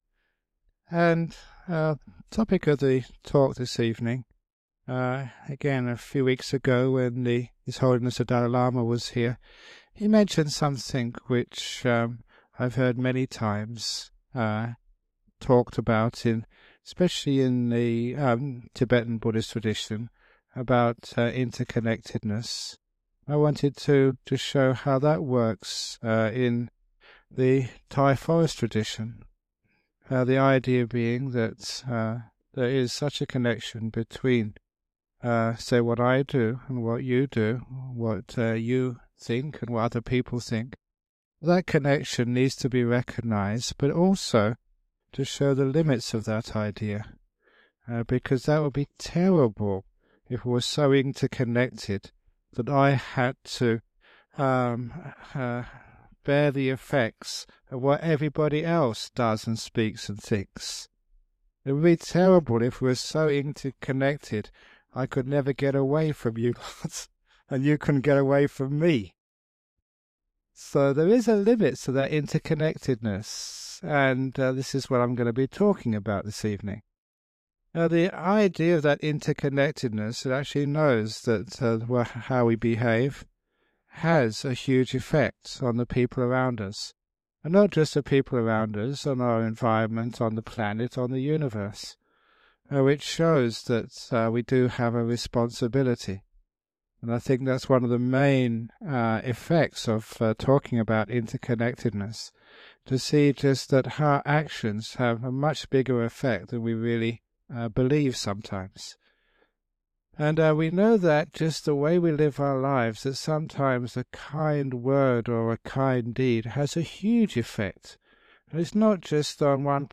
--- This dhamma talk was originally recorded using a low quality MP3 to save on file size on 29th June 2007.